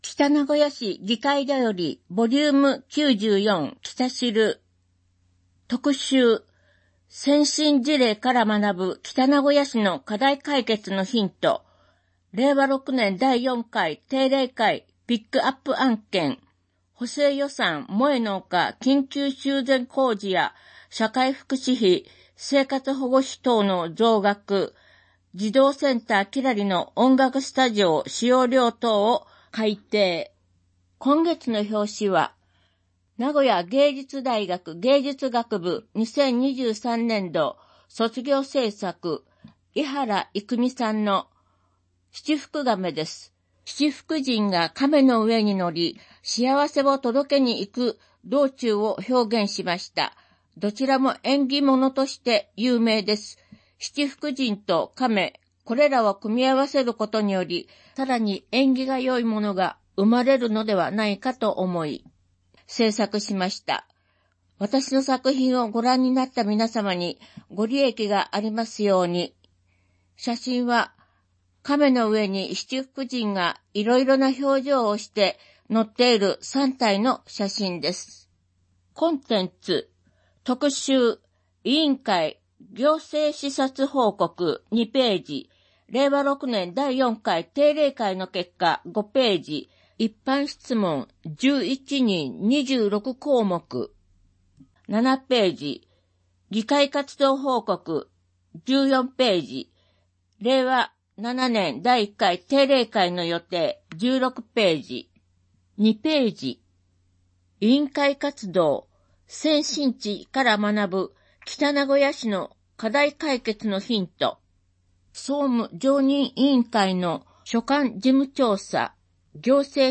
議会だより『きたしる』音声版 第94号